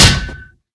Minecraft Version Minecraft Version snapshot Latest Release | Latest Snapshot snapshot / assets / minecraft / sounds / mob / zombie / metal1.ogg Compare With Compare With Latest Release | Latest Snapshot
metal1.ogg